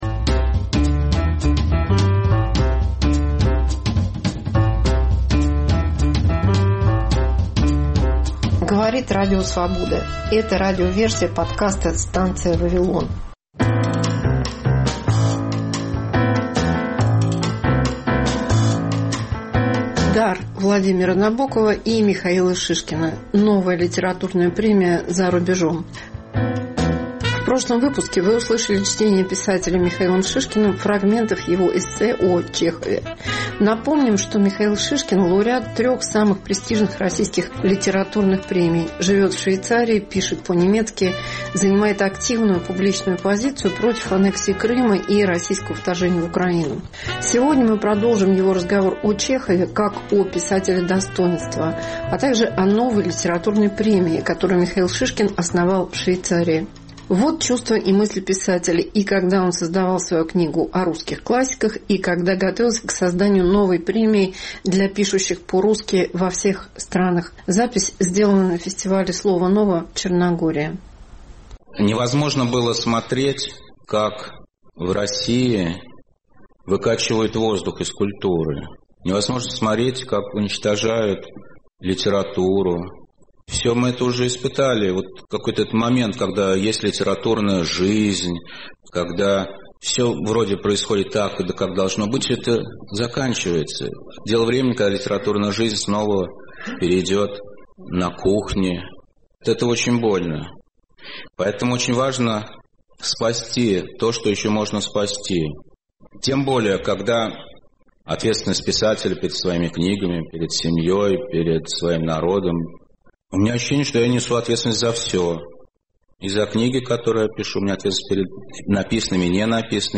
Елена Фанайлова в политическом кабаре эпохи инстаграма. Мегаполис Москва как Радио Вавилон: современный звук, неожиданные сюжеты, разные голоса